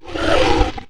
chickenbig.wav